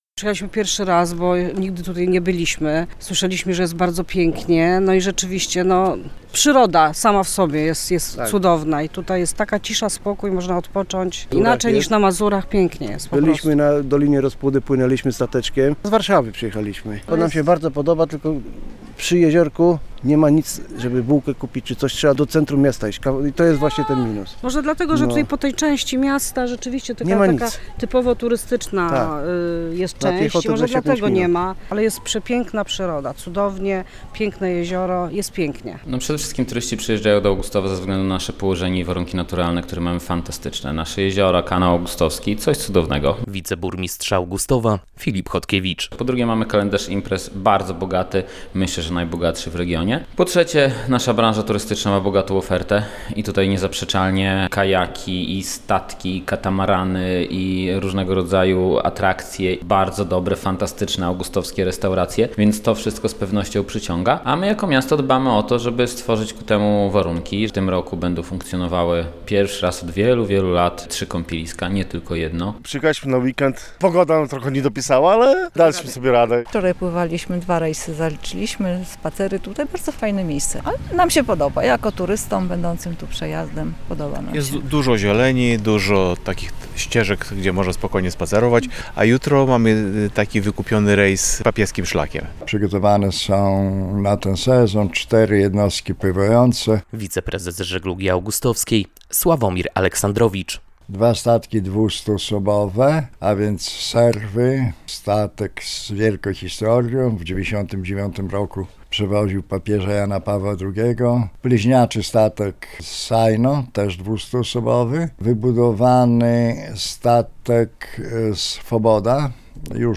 Jak mówi wiceburmistrz Filip Chodkiewicz - w Augustowie nikt nie będzie się nudził.
Mamy też wykupiony rejs papieskim szlakiem - mówią turyści.